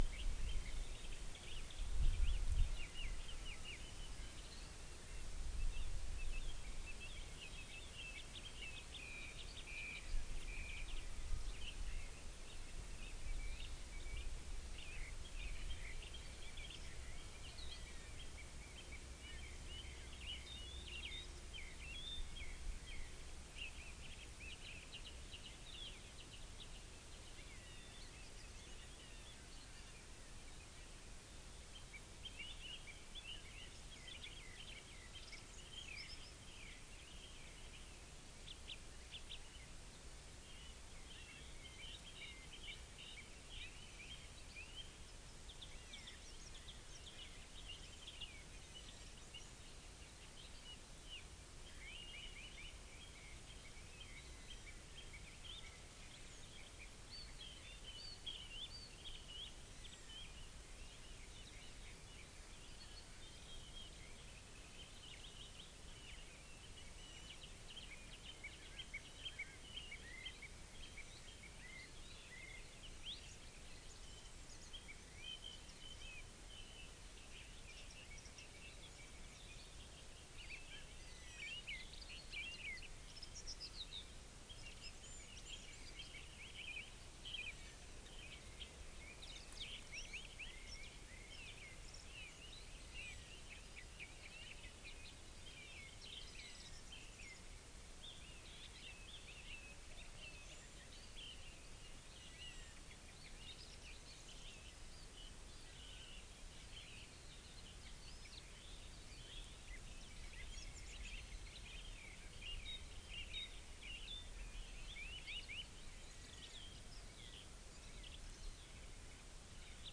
Columba palumbus
Turdus philomelos
Fringilla coelebs
Emberiza citrinella
Milvus milvus
Alauda arvensis